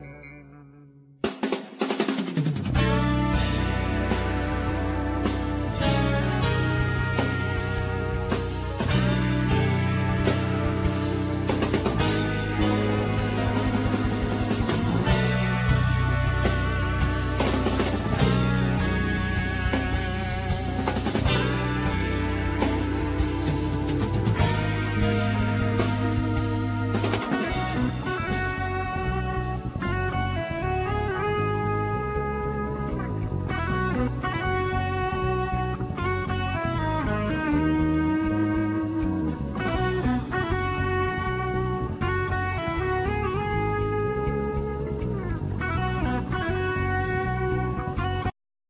Bass
Trumpet
Keyboard
Guitar
Fretless Bass
Drums
Percussions
Accordion
Trombone
Alto saxophone